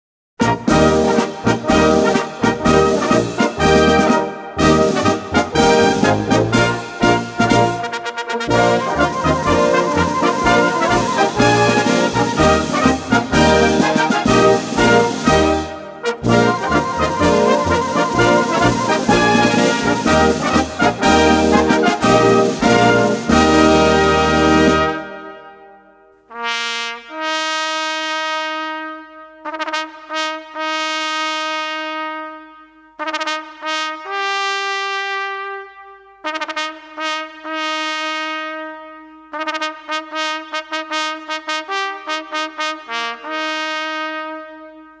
Gattung: Solo für drei Trompeten und Blasorchester
Besetzung: Blasorchester
Berühmtes Trompetensolo mit drei Posten.